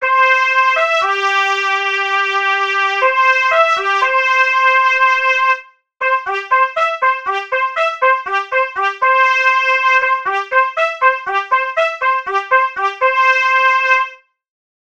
Oddílové signály
na trubku nás provázely celým táborem.
Nástup.wav